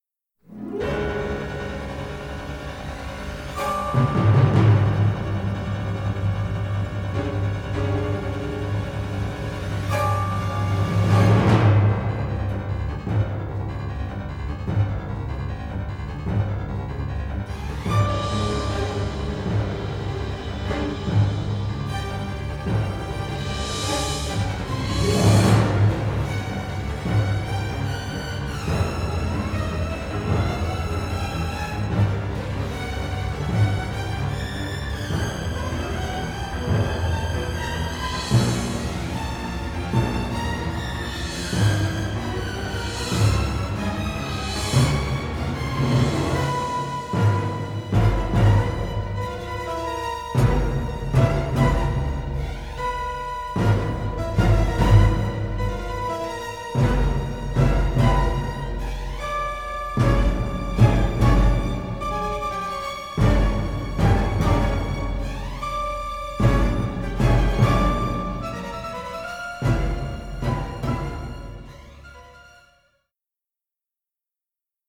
dark thriller score
original stereo session mixes